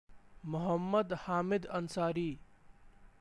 Hamid_Ansari_Pronunciation.ogg.mp3